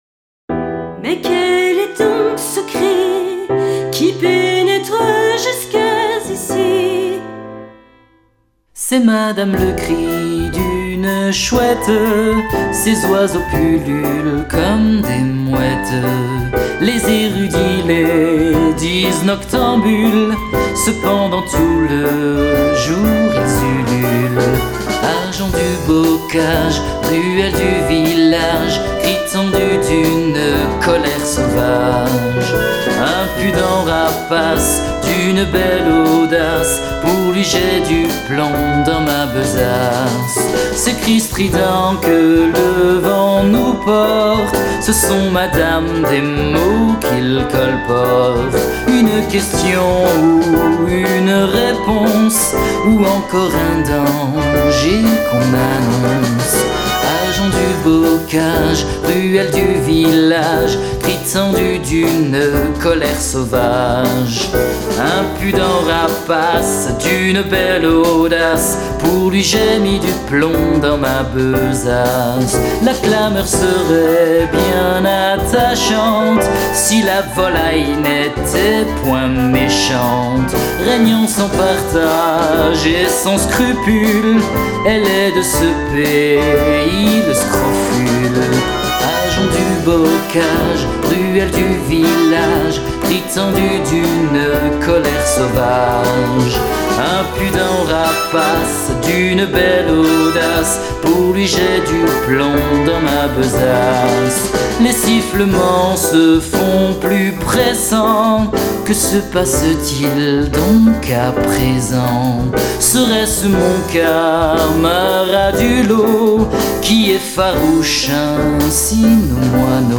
Ci-dessous 5 extraits du drame musical Marie de Verneuil (2000-2005), placés en suivant le déroulé du spectacle.
Le-cri-des-Chats-huants-voix-et-bande-son-IUAV.mp3